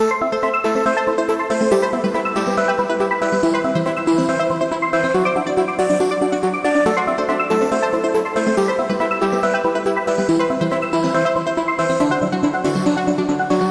标签： 140 bpm Dance Loops Synth Loops 1.15 MB wav Key : Unknown
声道立体声